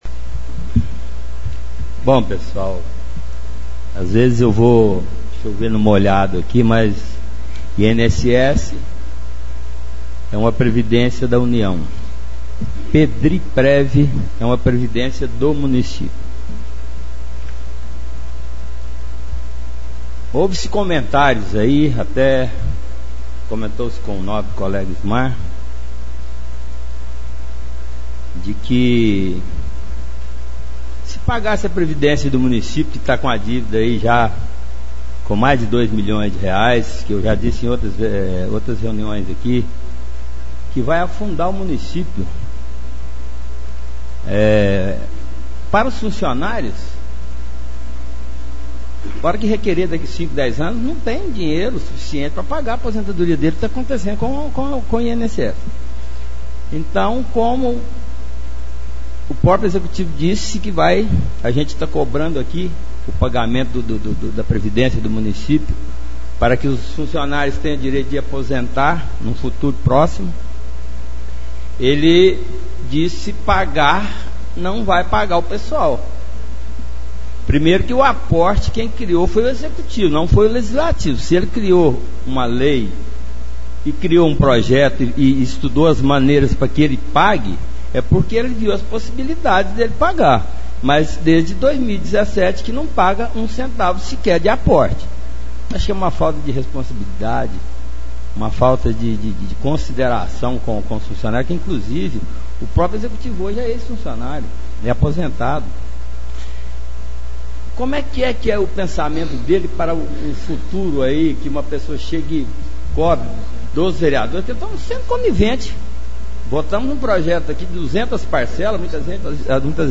Oradores das Explicações Pessoais (6ª Ordinária da 3ª Sessão Legislativa da 14ª Legislatura)